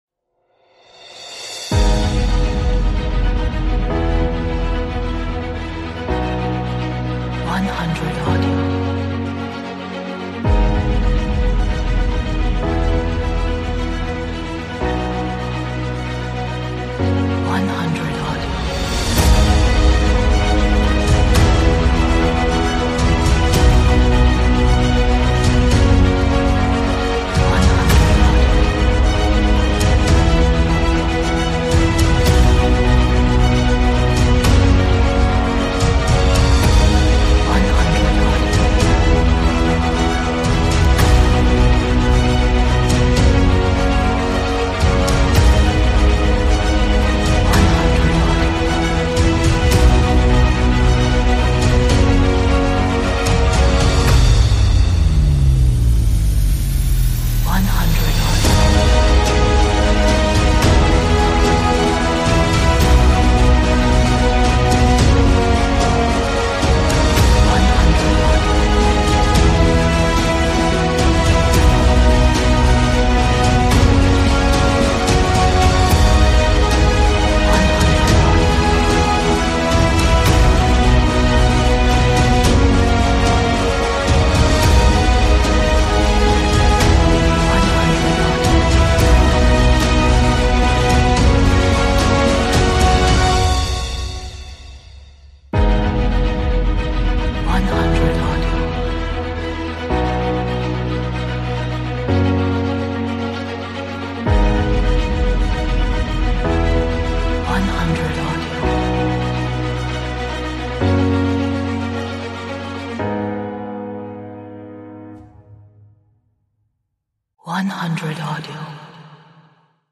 Atmospheric, dramatic, epic, energetic, romantic,